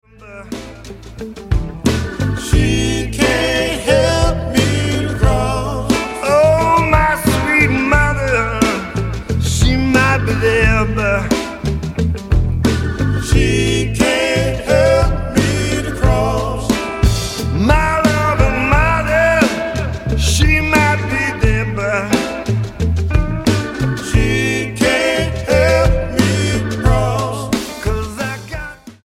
STYLE: Blues